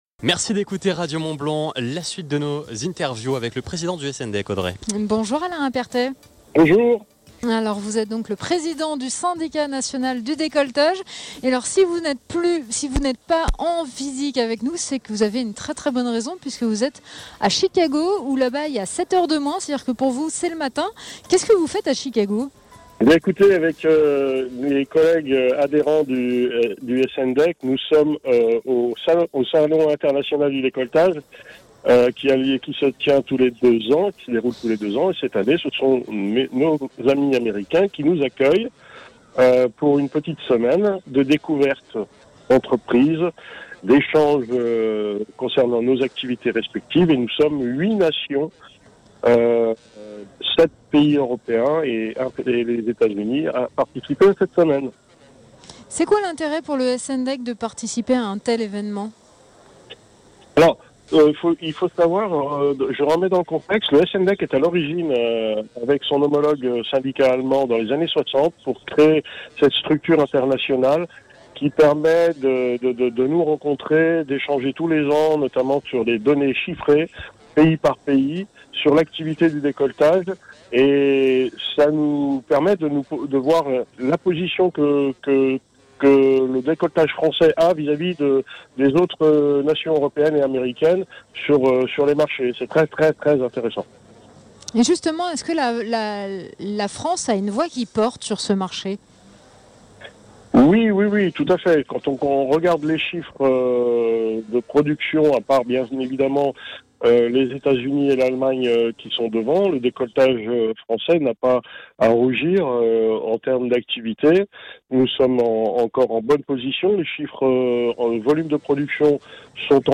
Nous étions en direct du Parc des Expositions de La Roche-sur-Foron, à l’occasion de la première édition de l’Indus’Festival.